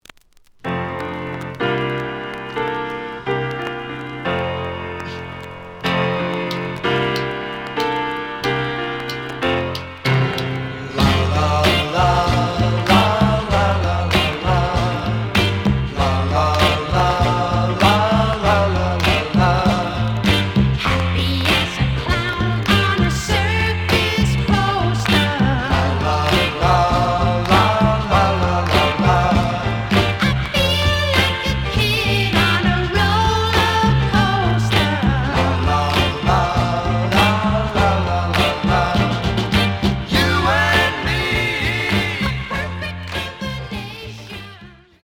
試聴は実際のレコードから録音しています。
●Genre: Rhythm And Blues / Rock 'n' Roll
両面に傷によるクリックノイズあり。